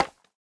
Minecraft Version Minecraft Version snapshot Latest Release | Latest Snapshot snapshot / assets / minecraft / sounds / block / bamboo / place3.ogg Compare With Compare With Latest Release | Latest Snapshot